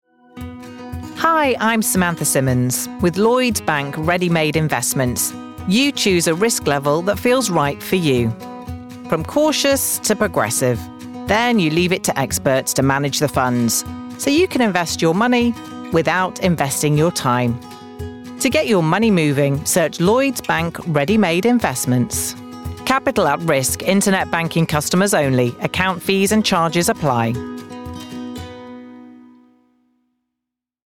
She has a very engaging, conversational and warm tone to her voice.
• Female
• Heightened RP
• Standard English R P
Showing: Commerical Clips
Warm, Trustworthy